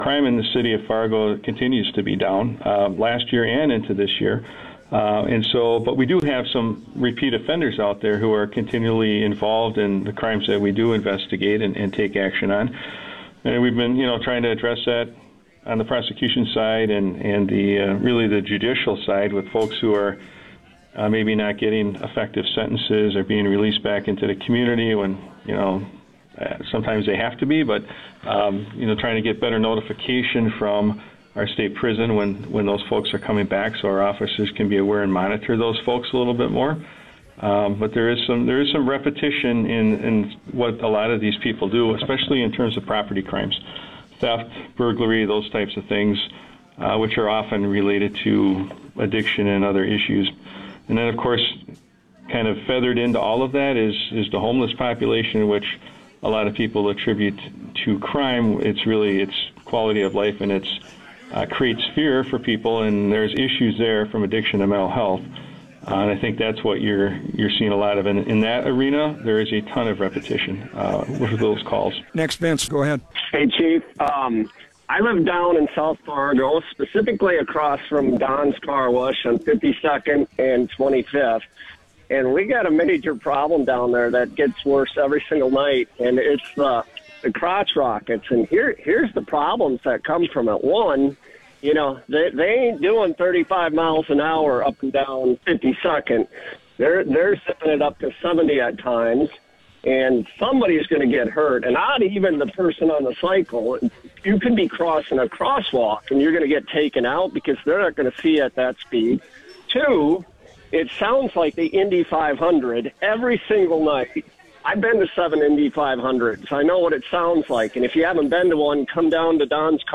Listen:  Fargo Police Chief David Zibolski discusses the crime situation on The Flag’s What’s On Your Mind